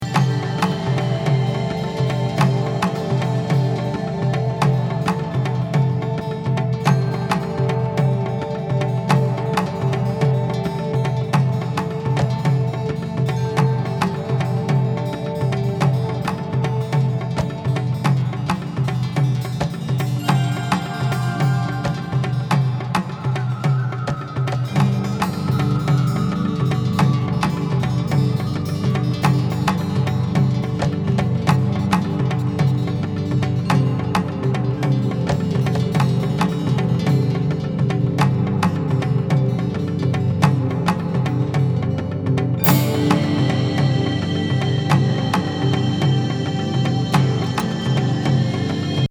深淵東洋スピリチュアル・ディープ・